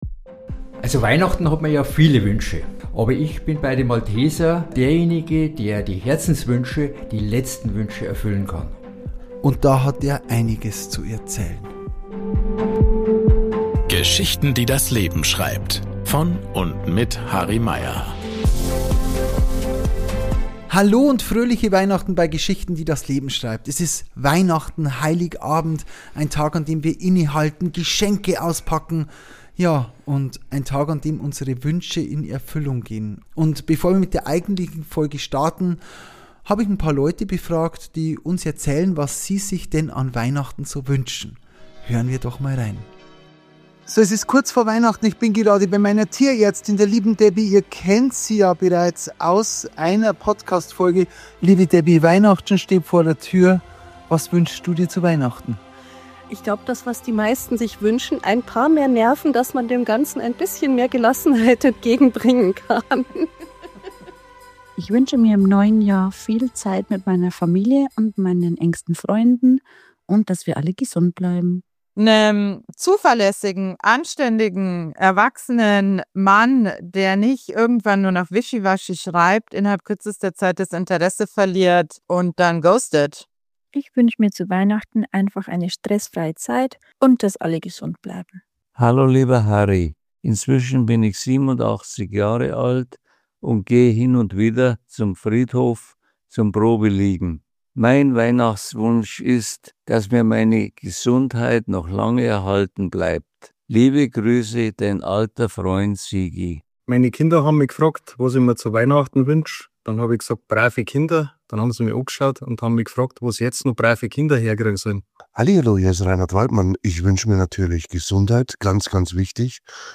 In dieser besonderen Weihnachtsfolge von Geschichten, die das Leben schreibt kommen zu Beginn Hörerinnen und Hörer selbst zu Wort und teilen ihre ganz persönlichen Weihnachtswünsche.